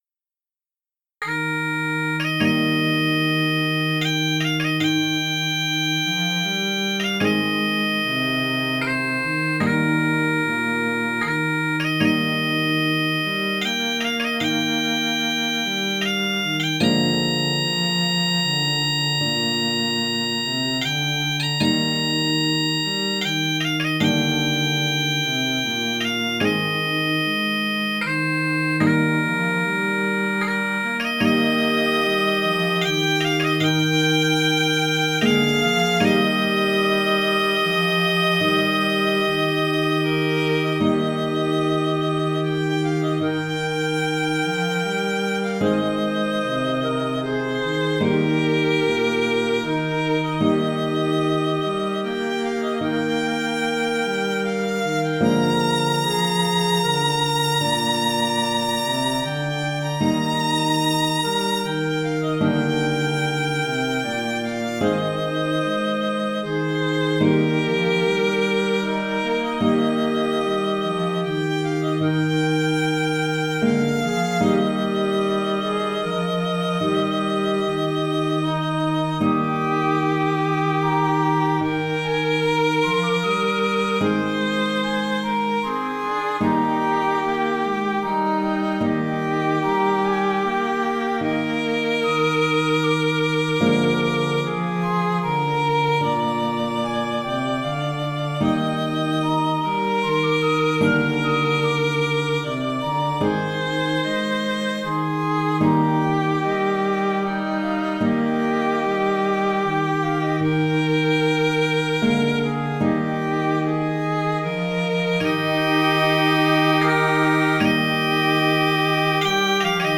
Du point de vue musical, la mélodie est fondée sur la gamme pentatonique. La mélodie est tirée d'un très ancien cantique religieux, lui-même inspiré d'airs populaires d'origine inconnue. Le morceau présenté ici est composé de trois parties, la 1ère avec le thème original, la 2e est une variation à partir de la même harmonie, la 3e reprend le thème.